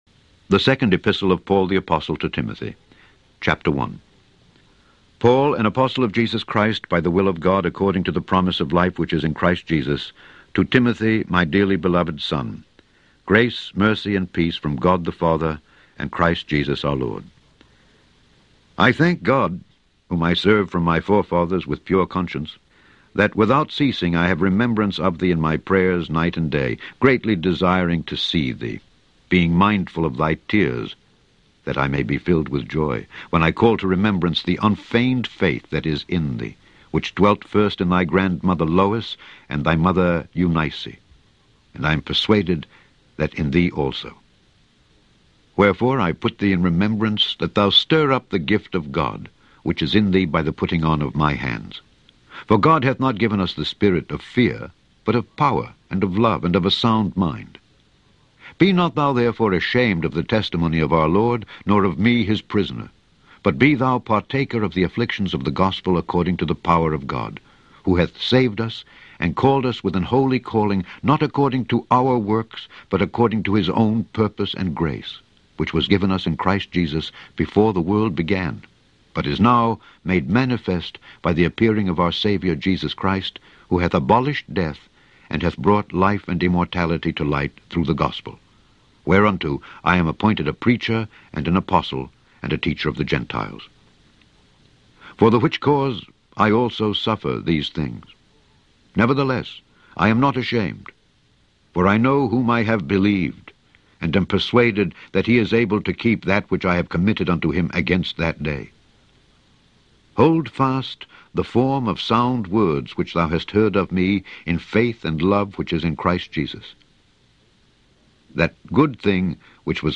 Scourby Audio Bible